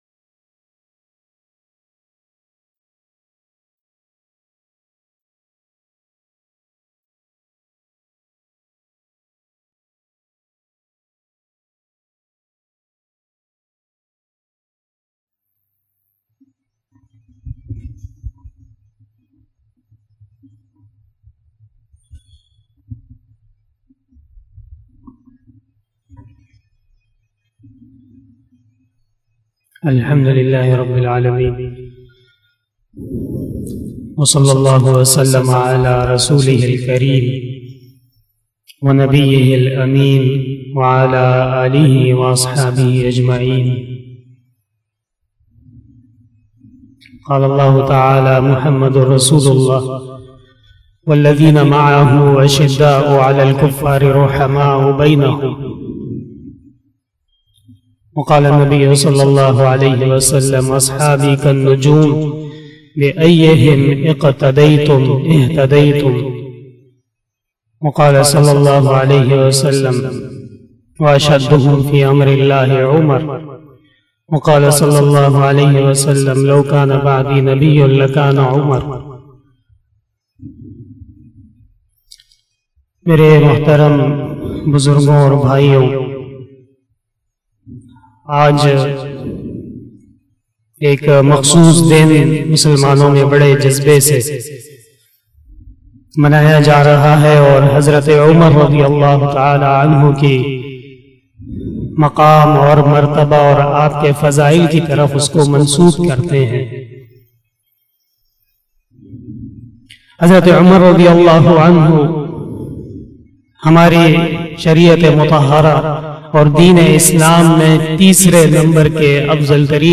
041 After Isha Namaz Bayan 10 August 2021 (01 Muharram 1443HJ) Tuesday